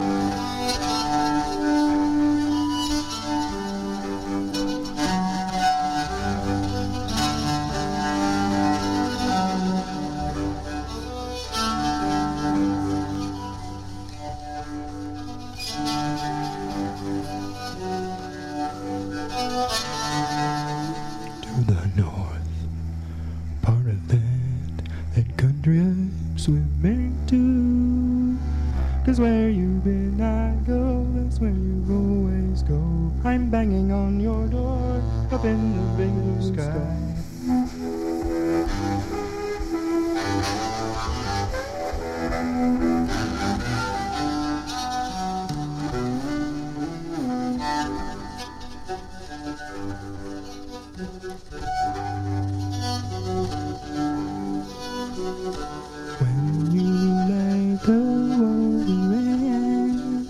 リズムマシーン、エフェクター、チェロ、声のみの編成で